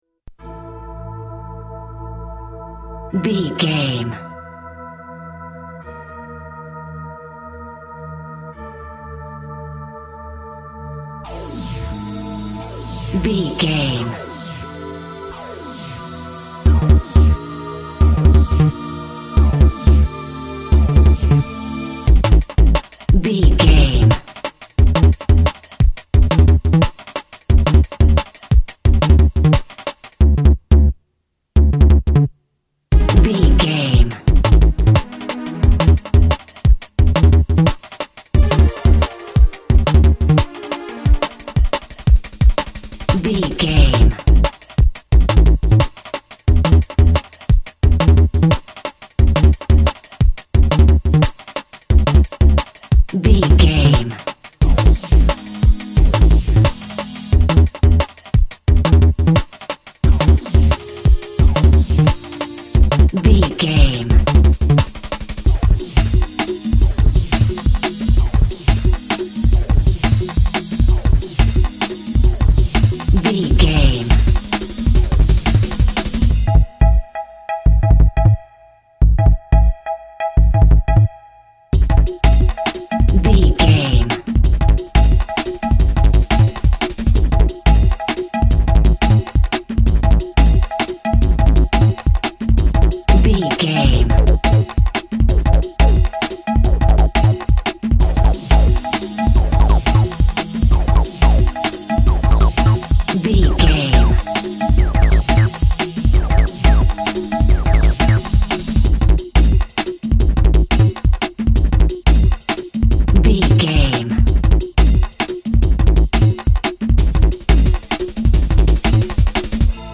Epic / Action
Fast paced
Aeolian/Minor
Fast
intense
energetic
drums
drum machine
house
electro
techno
trance
synth lead
synth bass
Synth Pads